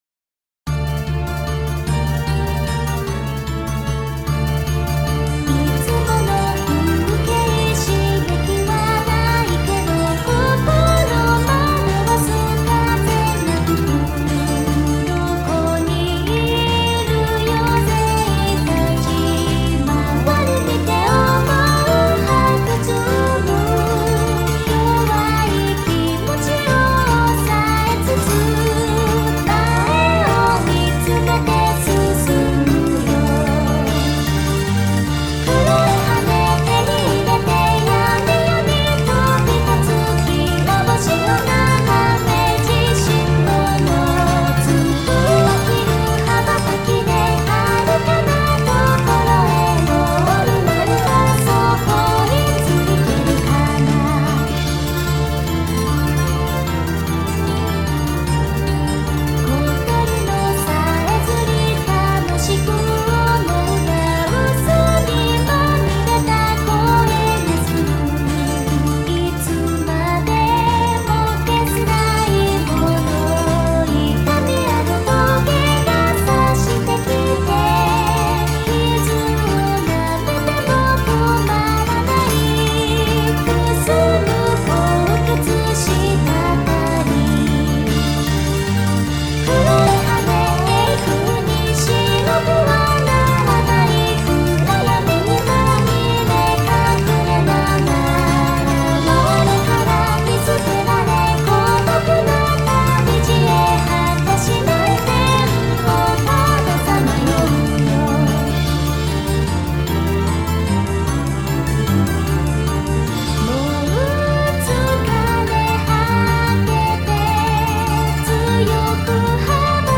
〜ボーカル版〜